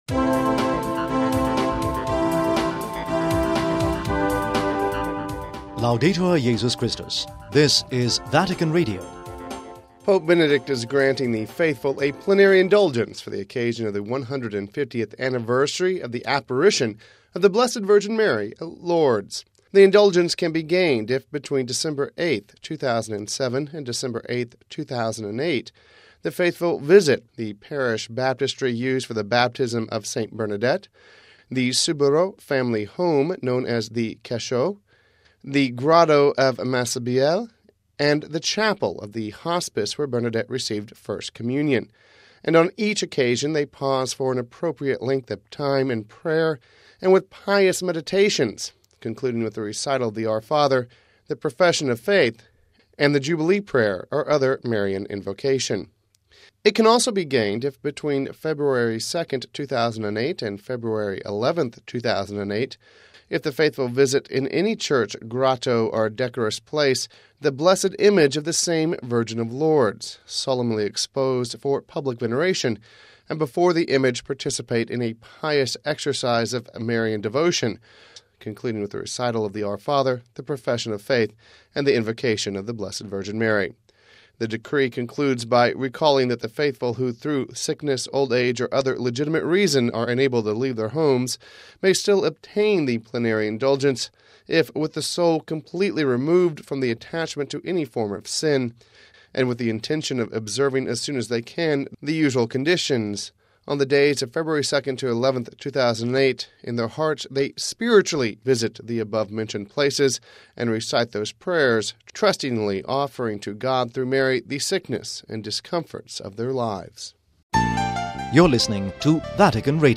(6 Dec 07 - RV) Pope Benedict is granting the faithful a plenary Indulgence for the occasion of the 150th anniversary of the apparition of the Blessed Virgin Mary at Lourdes. We have this report...